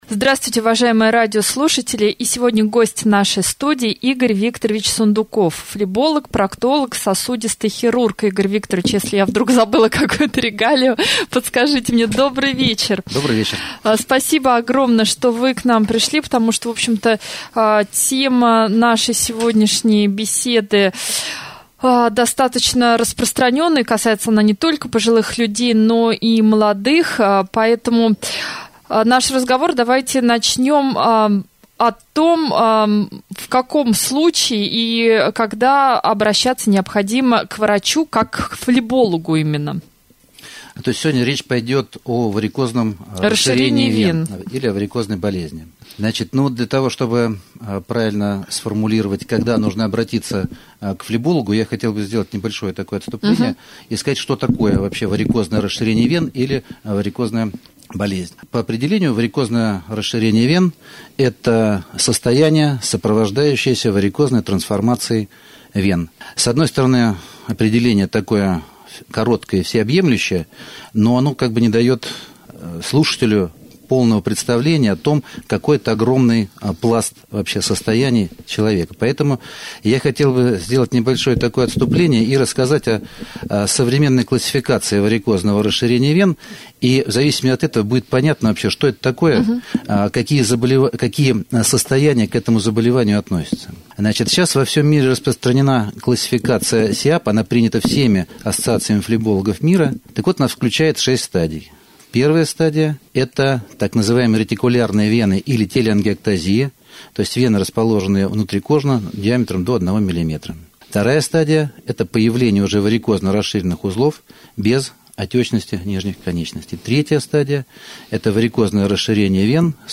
prjamoj-jefir-2.mp3